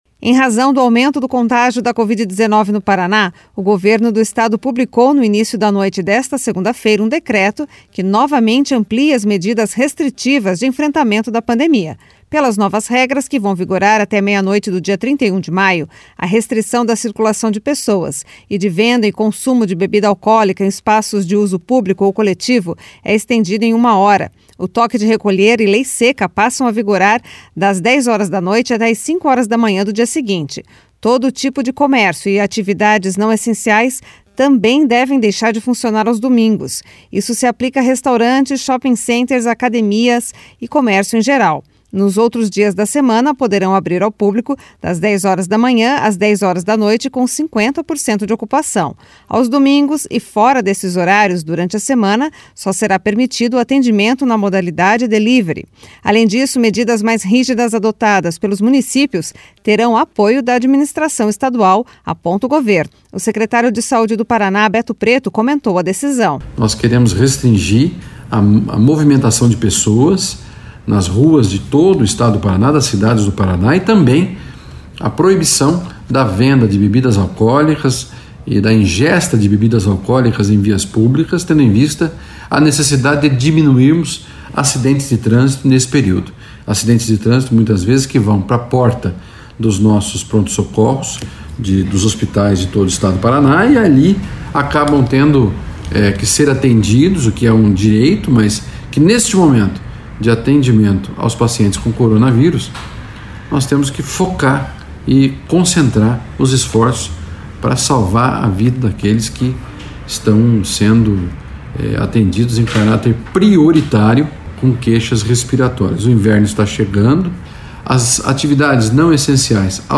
O secretário de Saúde do Paraná, Beto Preto, comentou a decisão.